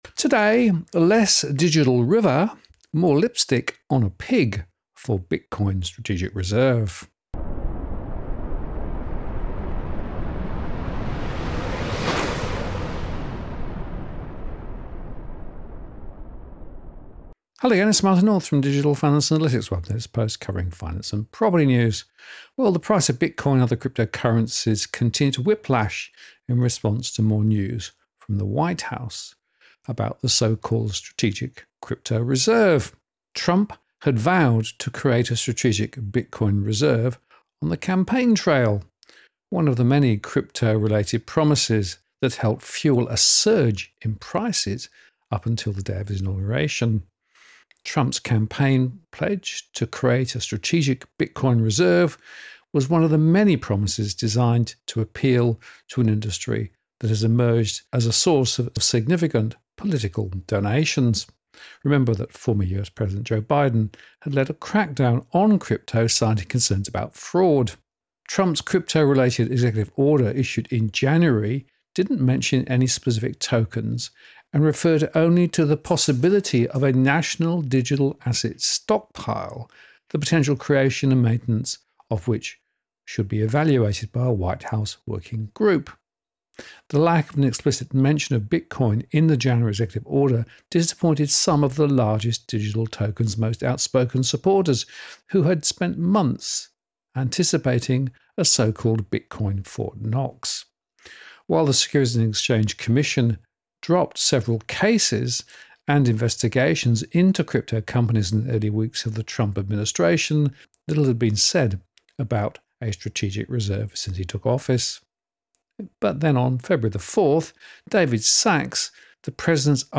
Digital Finance Analytics (DFA) Blog DFA Live Q&A HD Replay CBDC Vs Crypto: Who Wins?